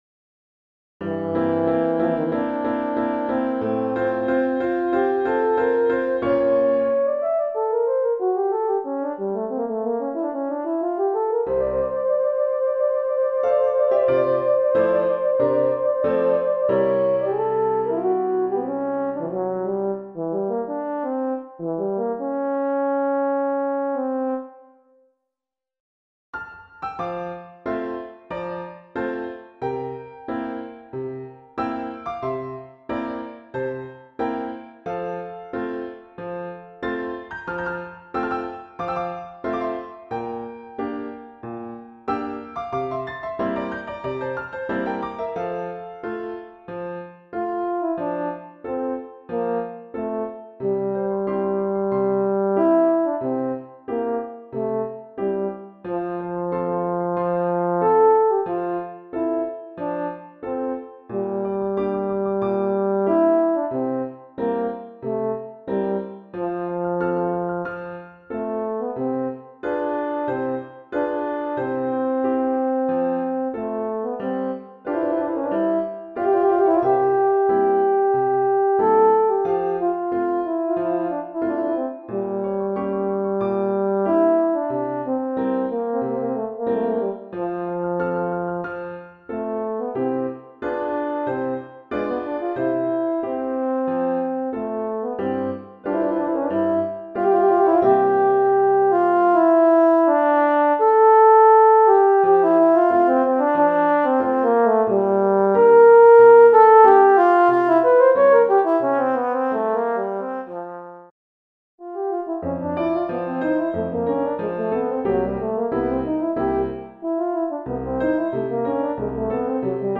Voicing: Horn Solo